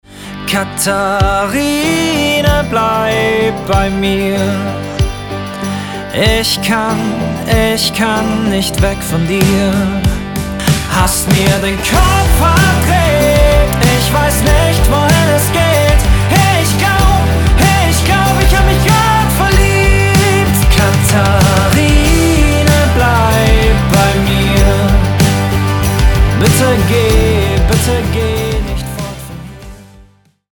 Mischung aus Pop, Gefühl und Energie
Genre: POP